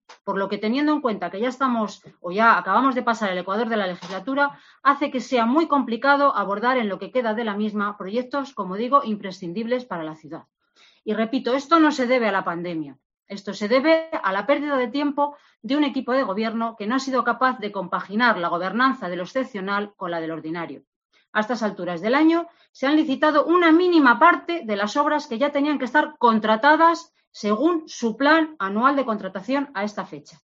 Yolanda Vázquez, portavoz PSOE. Pleno debate estado ciudad